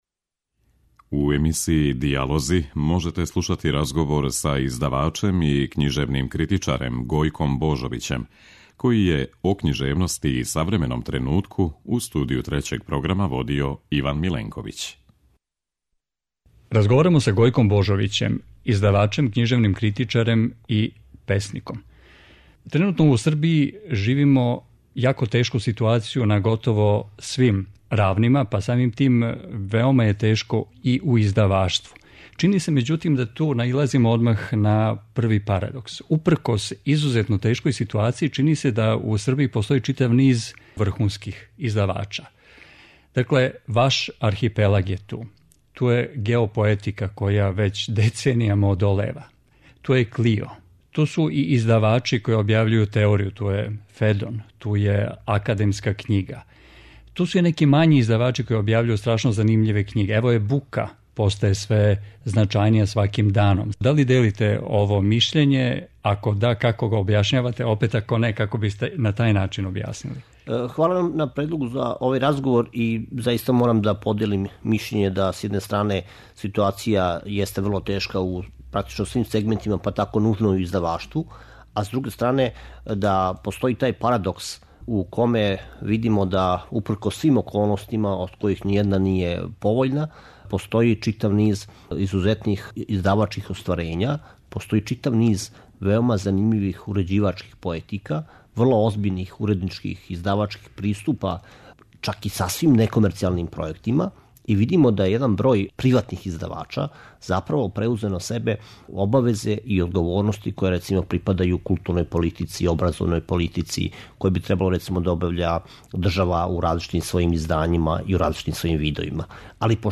У емисији ДИЈАЛОЗИ можете слушати разговор који је